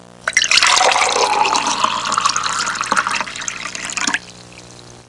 Filling A Glass Sound Effect
filling-a-glass.mp3